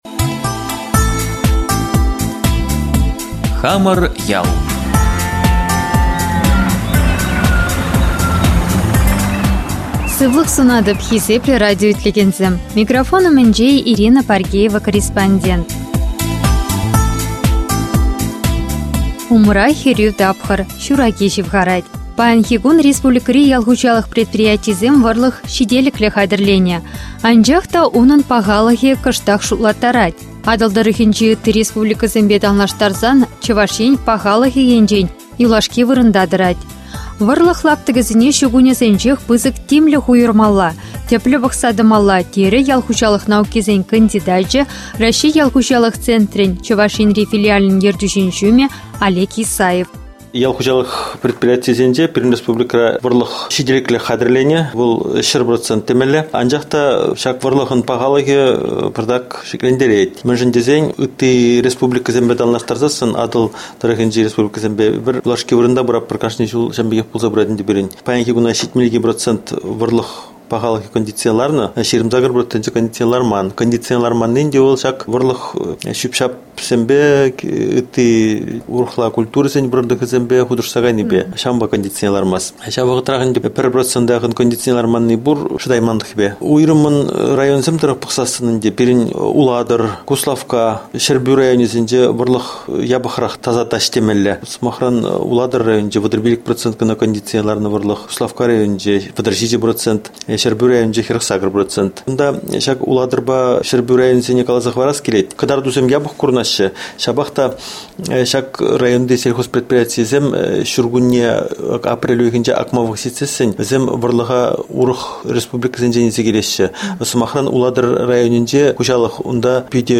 Выступления